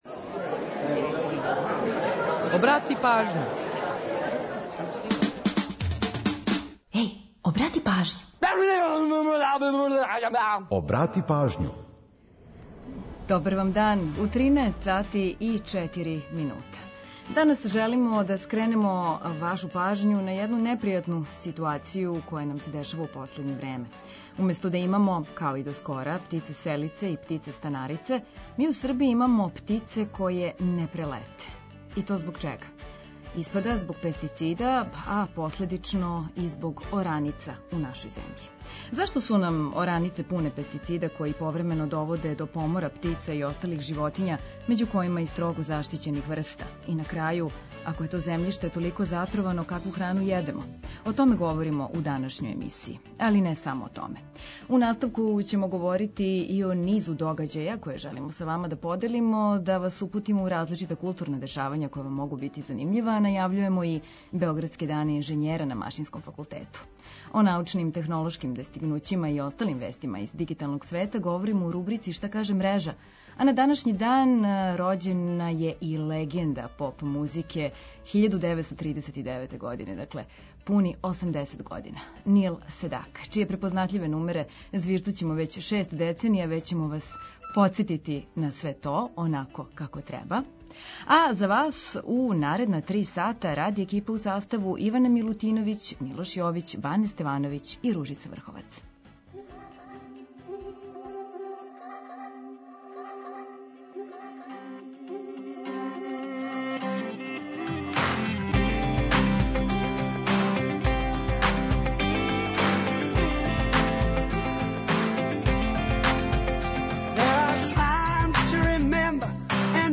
Ако штетимо птицама, штетимо и себи, причају наши саговорници, орнитолози и заштитари природе. Од 13 часова чућете како можемо да поправимо ситуацију и које су последице некажњавања загађивача земљшта.